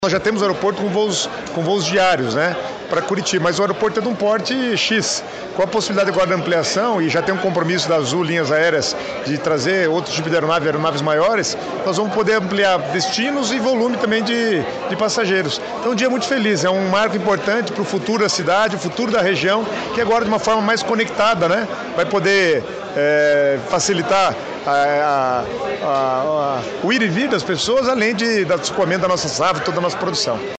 Sonora do secretário Estadual do Planejamento, Guto Silva, sobre o novo terminal do Aeroporto de Pato Branco